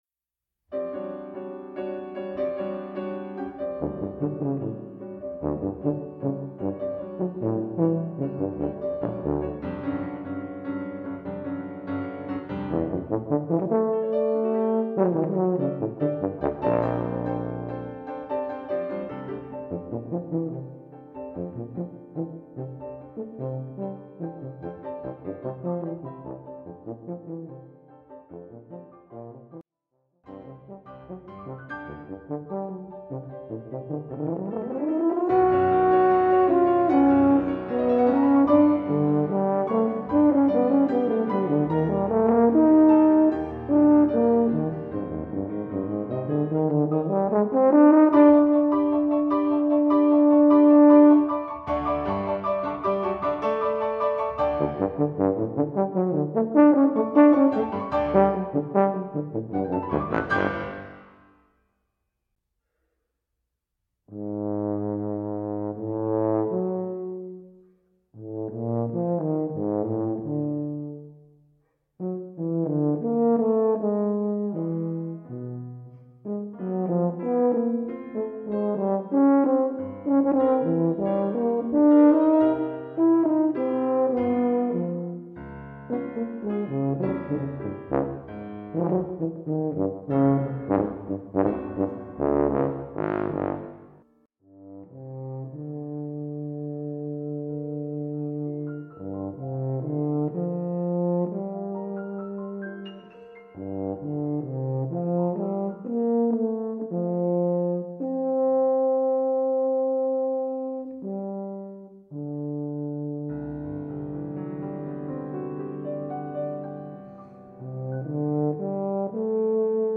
For Tuba Solo
Arranged by . with Piano.